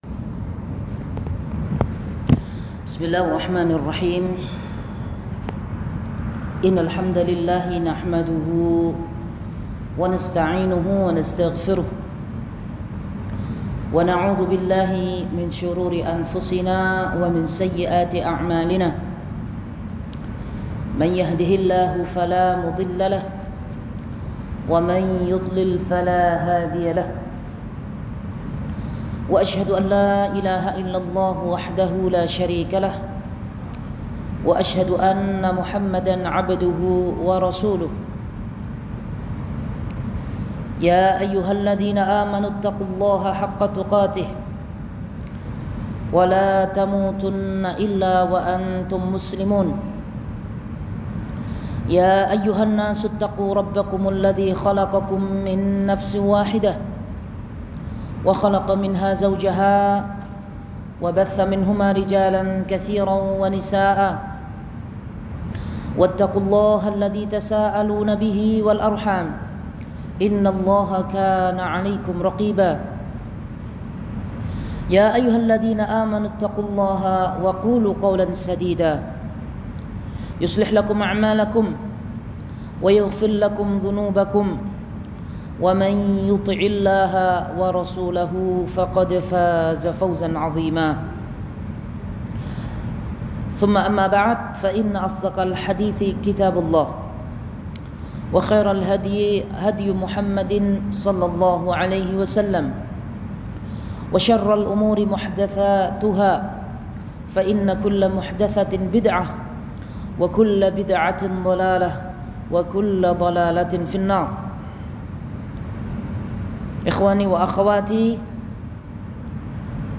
Kajian Sabtu – Barwa Village Barwa Village